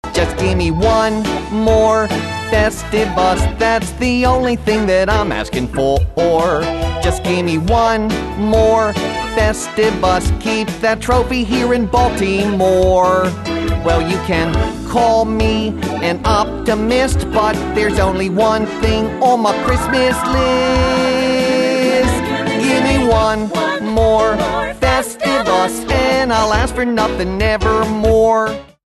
This hilarious collection of novelty songs includes